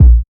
Tight Lows Kick A# Key 547.wav
Royality free kick tuned to the A# note. Loudest frequency: 74Hz
.WAV .MP3 .OGG 0:00 / 0:01 Type Wav Duration 0:01 Size 37,79 KB Samplerate 44100 Hz Bitdepth 24 Channels Mono Royality free kick tuned to the A# note.
tight-lows-kick-a-sharp-key-547-Ux3.mp3